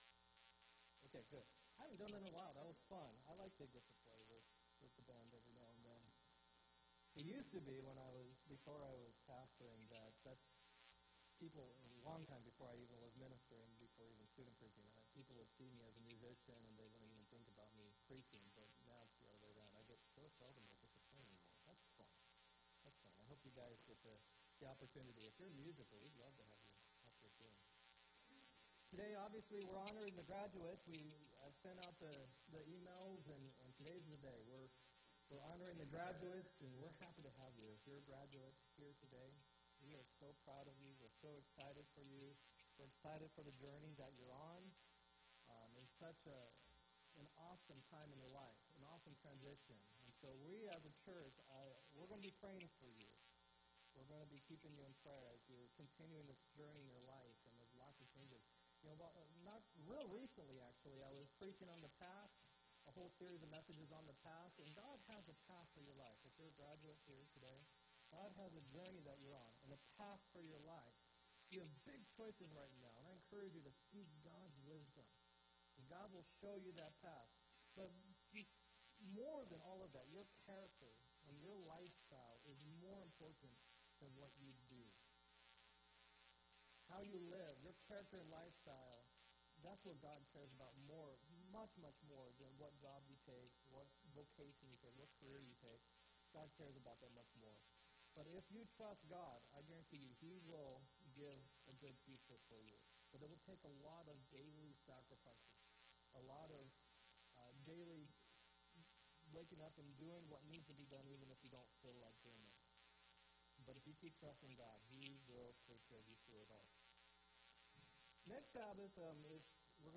6-10-17 sermon